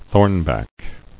[thorn·back]